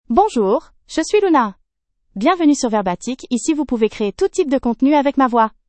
FemaleFrench (Canada)
LunaFemale French AI voice
Voice sample
Female
Luna delivers clear pronunciation with authentic Canada French intonation, making your content sound professionally produced.